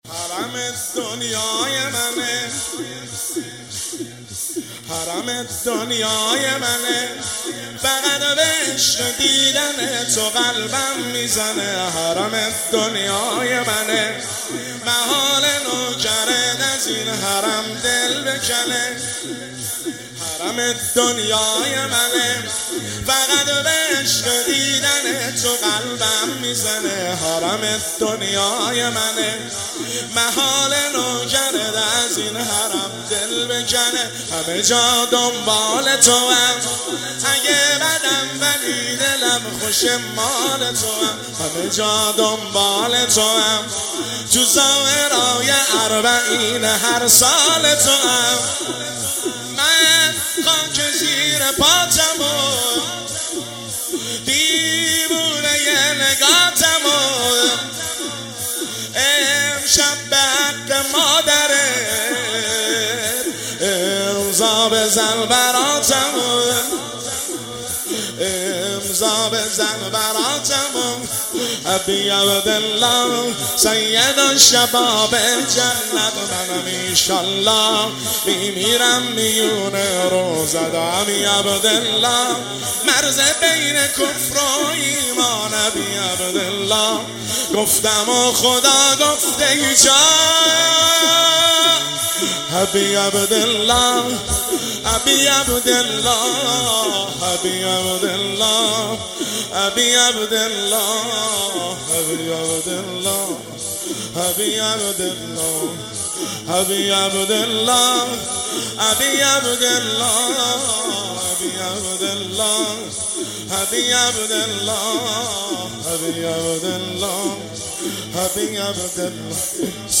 تک  شب هفتم محرم الحرام 1404
هیئت خادم الرضا قم